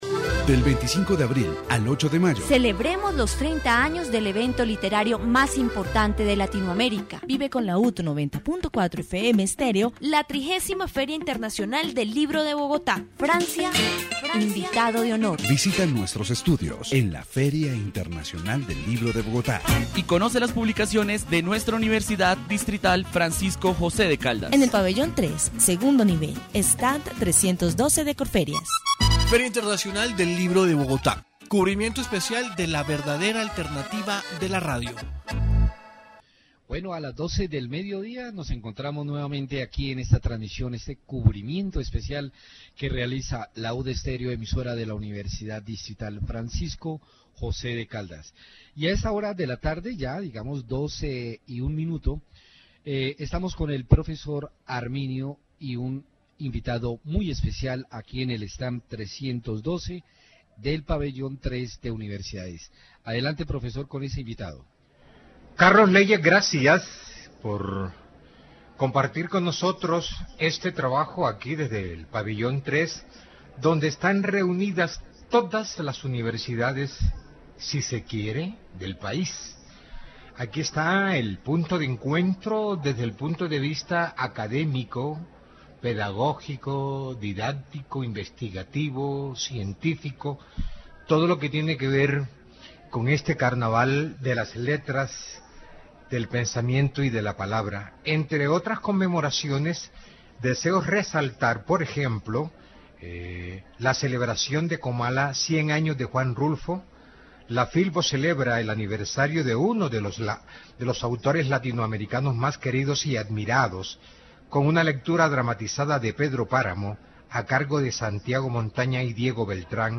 Informe radial